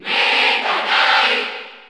Crowd cheers (SSBU) You cannot overwrite this file.
Meta_Knight_Cheer_Spanish_NTSC_SSB4_SSBU.ogg